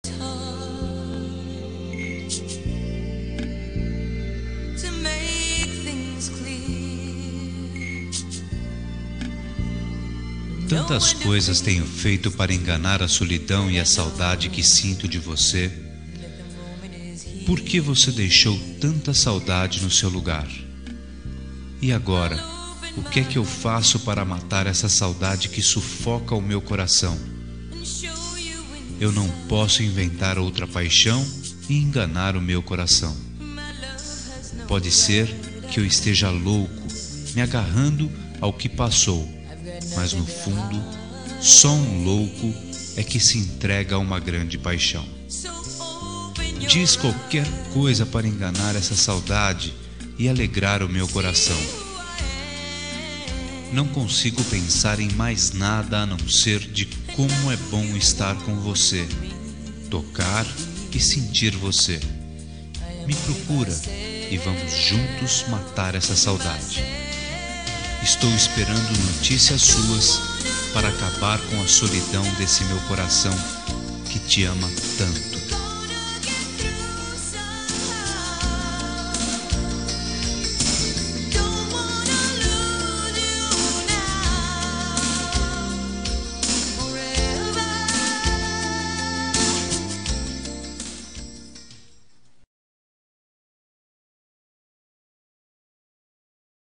Telemensagem de Saudades – Voz Masculina – Cód: 444